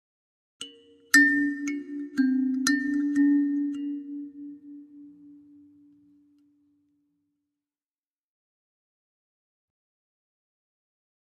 Kalimba, Accent, Type 4 - Slow